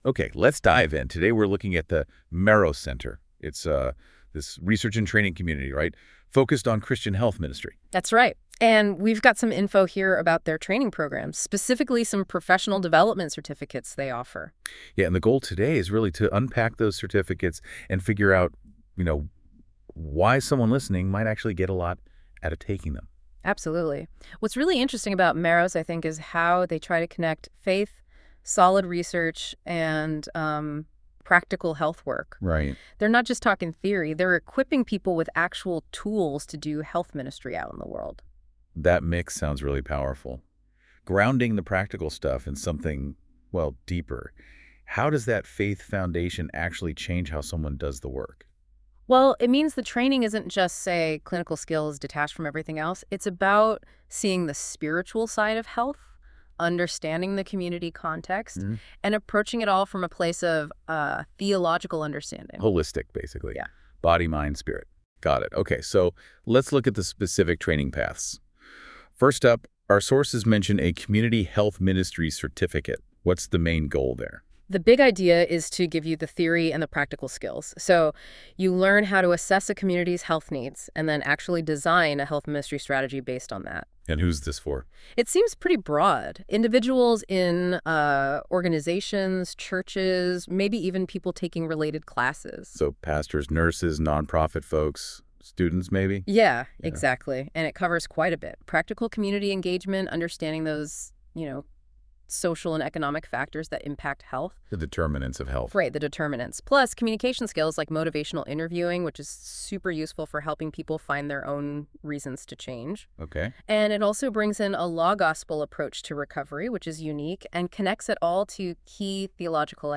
Why Take Meros Training? Listen to our AI podcast: